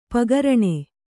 ♪ pagaraṇe